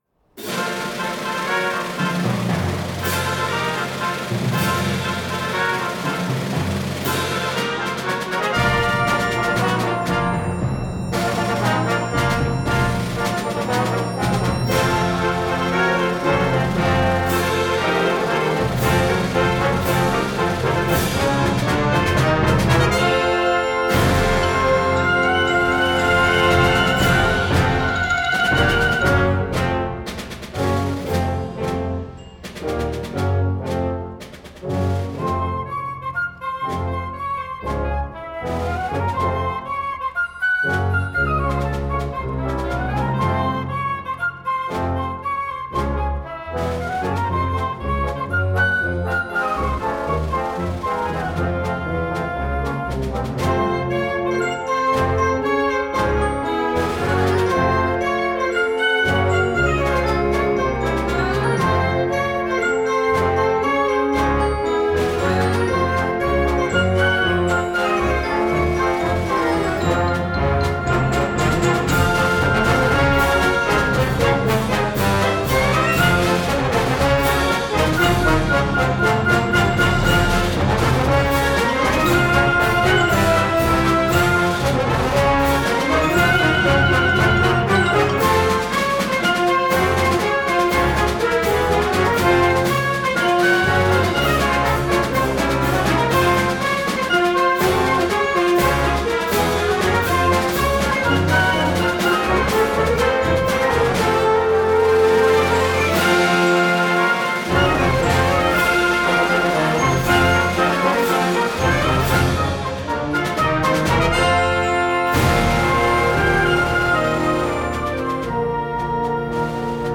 Gattung: Konzertmarsch für Blasorchester
Besetzung: Blasorchester